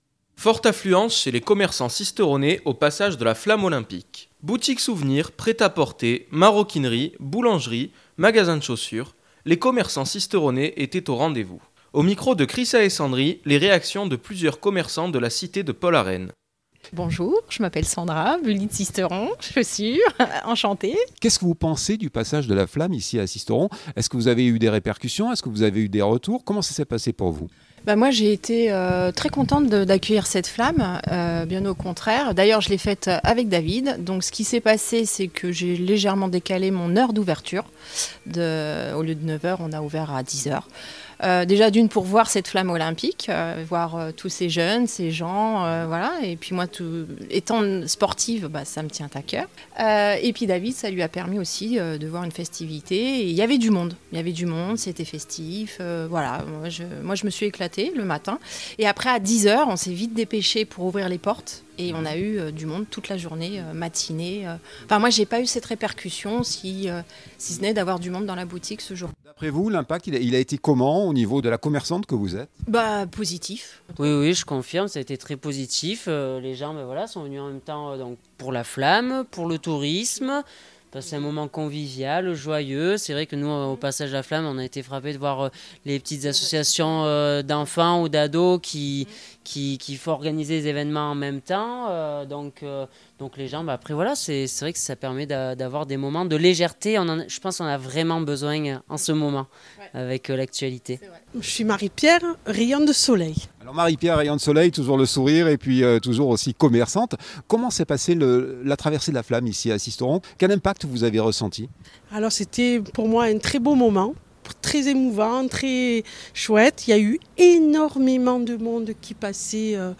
les réactions de plusieurs commerçants de la cité de Paul Arène.